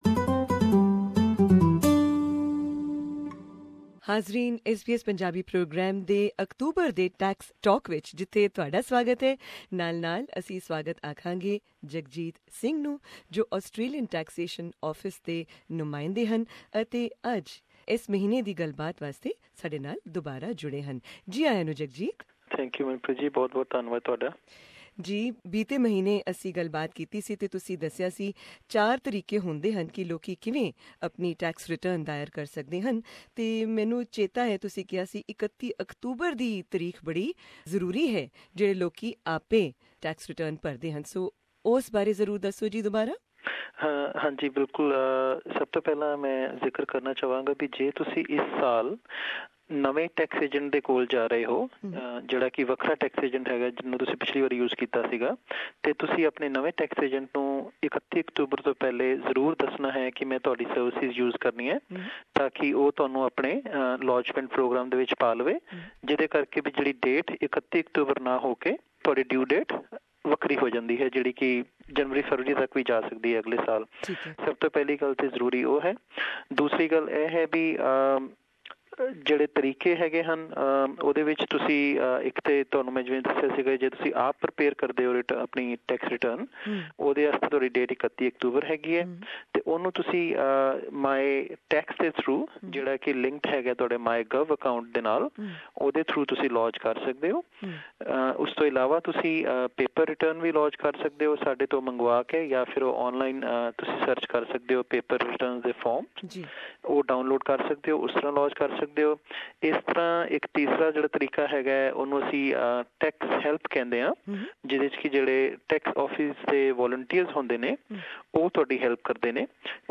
Tax Talk - a monthly radio segment, brought to you in Punjabi Source: ATO